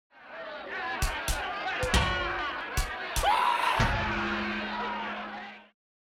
Cartoon Fight, Crowd, Short
Category 🗣 Voices
angry cartoony fight funny loud punching punch-up scream sound effect free sound royalty free Voices